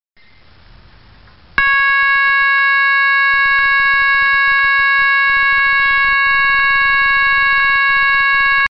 Serie: SIRENAS ELECTRÓNICAS
5 Sonidos independientes seleccionables
Gran rendimiento acústico - 110DB
Tono_3